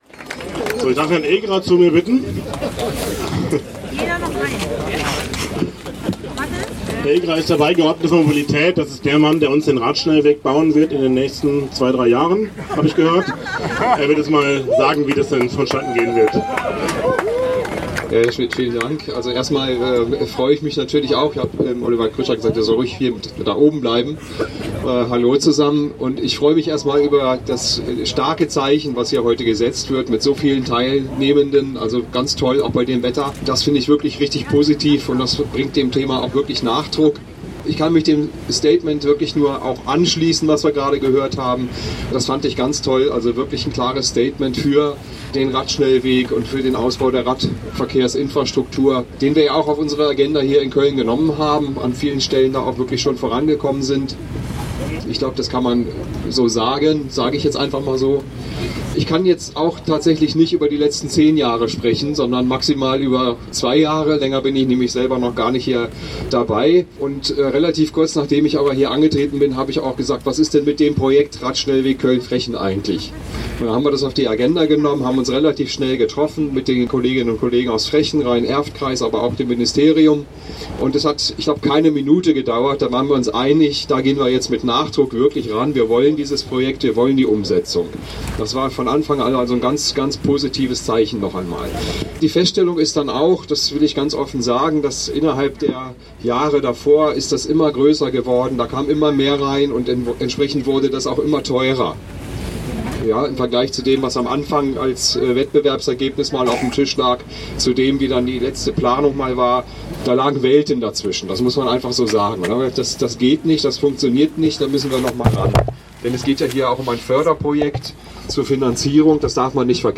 Kapitel 4: Abschlusskundgebung
Verkehrsdezernent Ascan Egerer zu den politischen Schritten, um dieses Radschnellwegprojekt umsetzen zu können (Audio 7/9) [MP3]